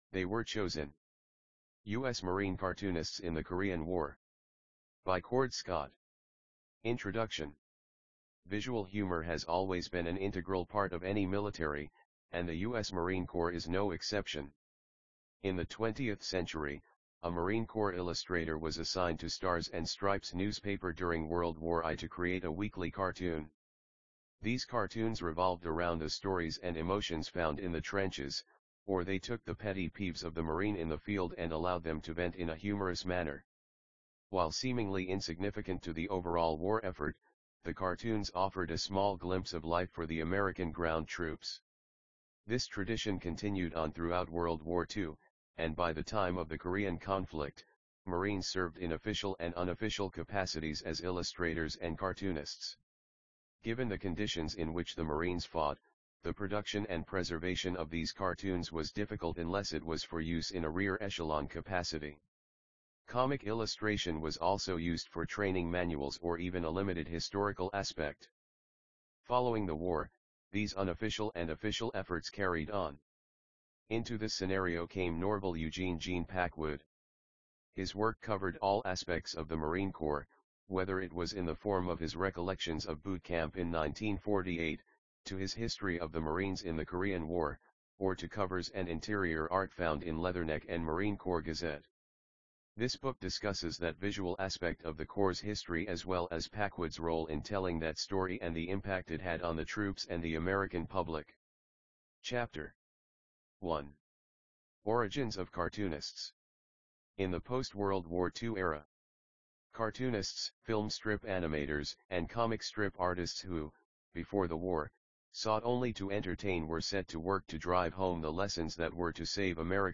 They Were Chosin_AUDIOBOOK.mp3